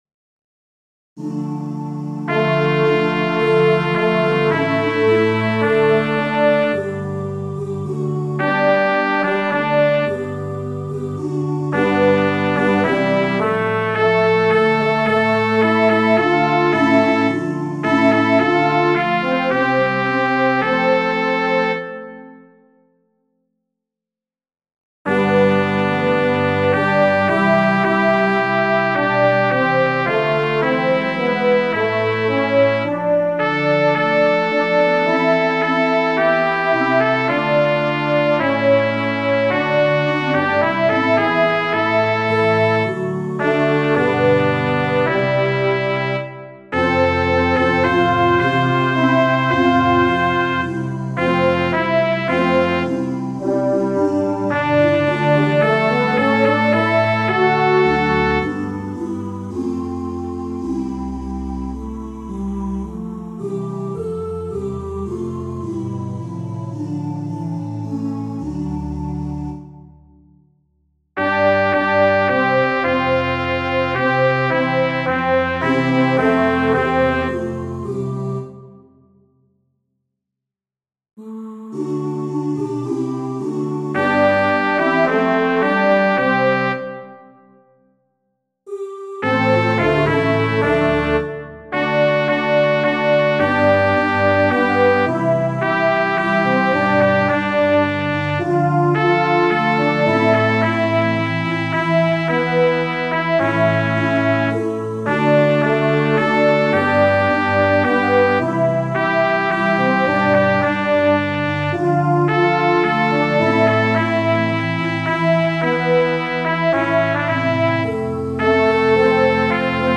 The human voices are “oh choir,” which sounds like we do when we sing on “doo.”
Sectional practice for women:
SA (Soprano is a trumpet, alto a french horn)